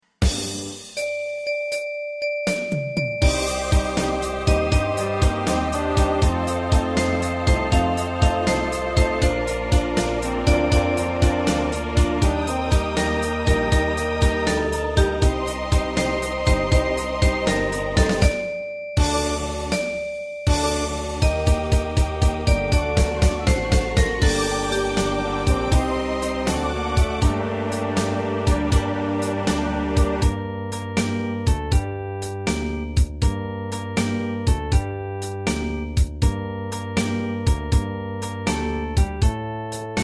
大正琴の「楽譜、練習用の音」データのセットをダウンロードで『すぐに』お届け！
カテゴリー: アンサンブル（合奏） .
日本のポピュラー